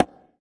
ECONGA TAP.wav